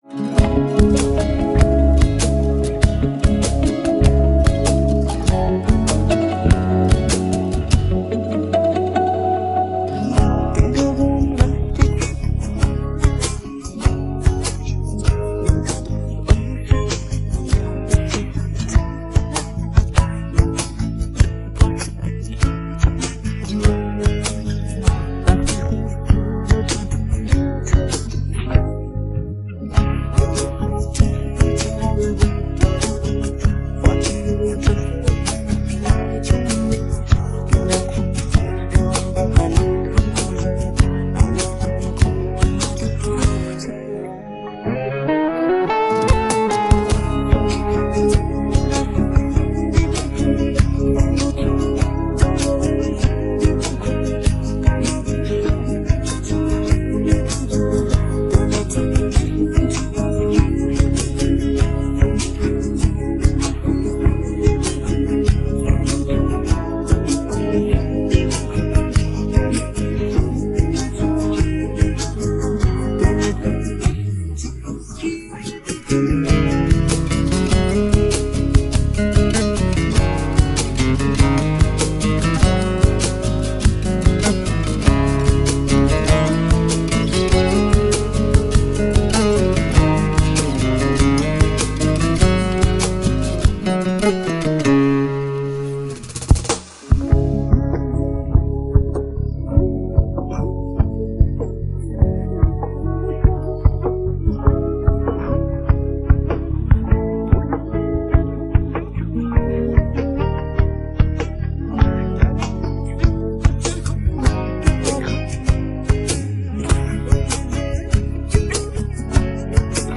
پخش نسخه بی‌کلام
download-cloud دانلود نسخه بی کلام (KARAOKE)